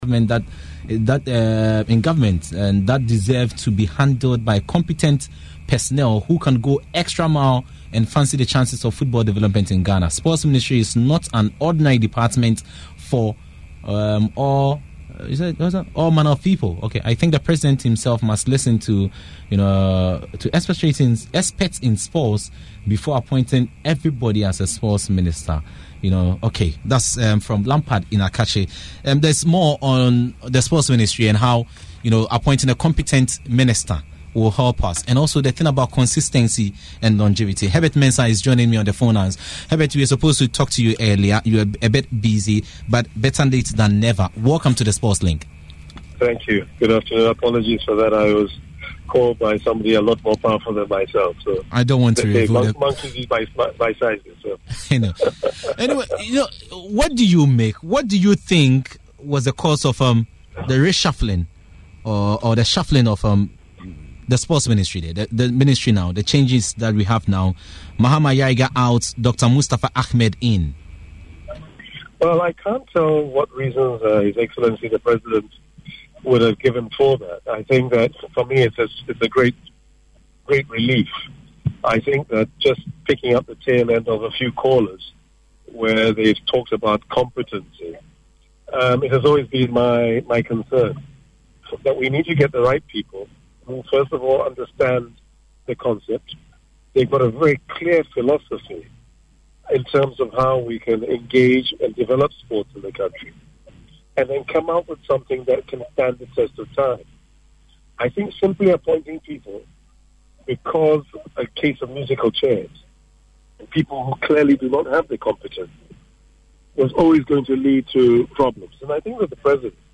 Interview with Joy FM on sports administration in Ghana